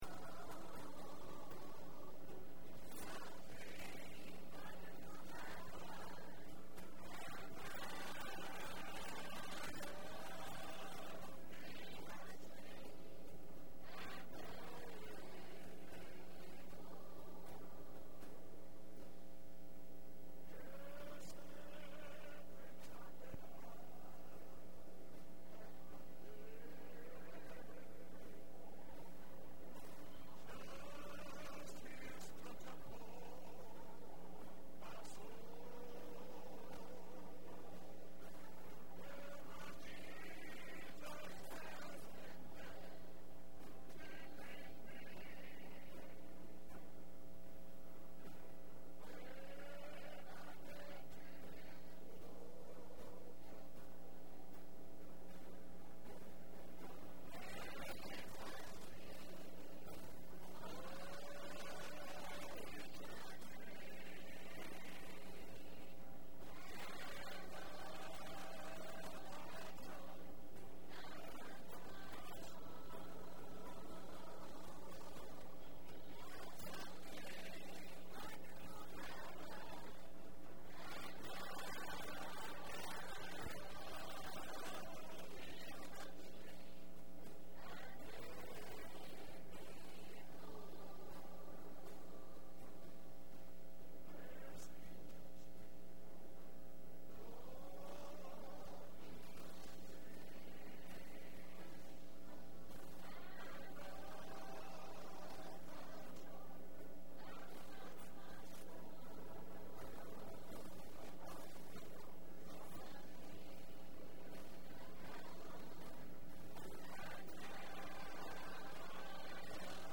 5/19/10 Wednesday Service
Evening Service: 100519_1A.mp3 , 100519_1B.mp3